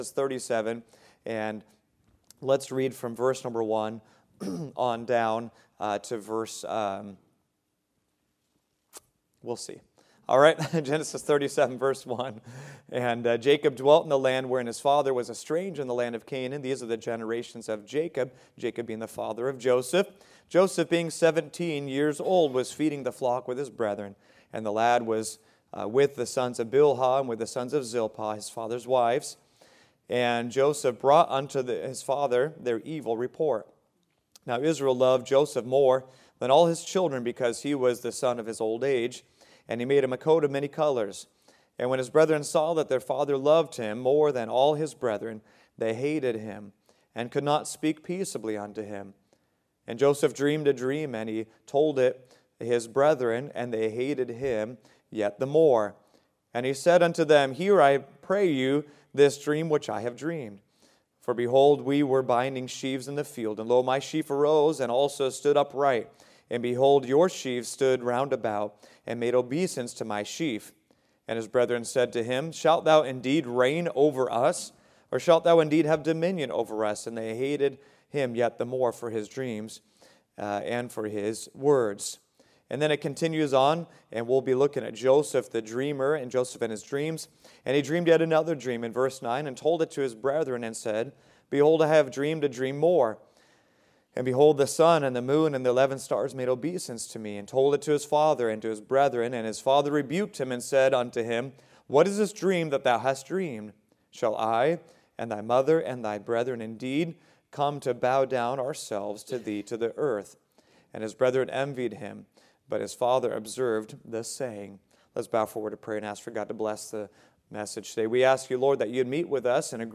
Joseph and His Dreams – Part 2 | Sunday School – Shasta Baptist Church